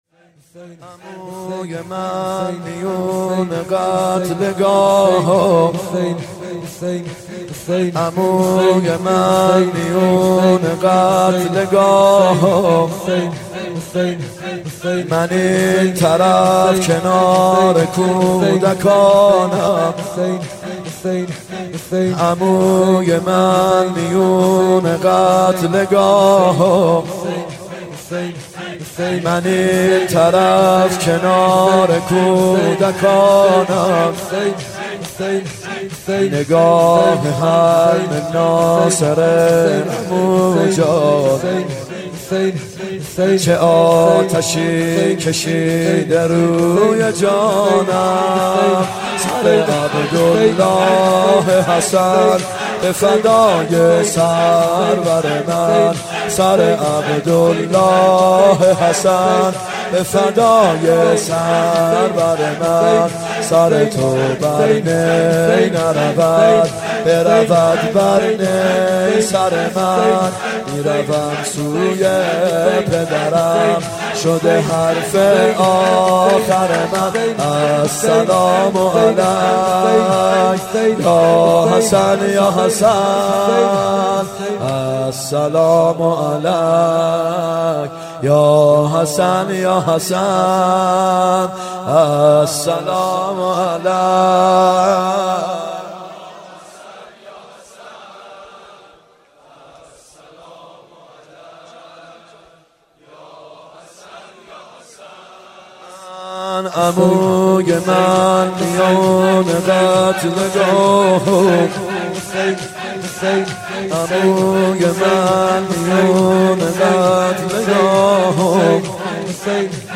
محرم 91 شب پنجم شور(عموی من میون قتلگاهو
محرم 91 ( هیأت یامهدی عج)